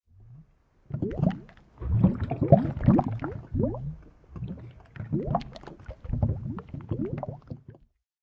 lava.ogg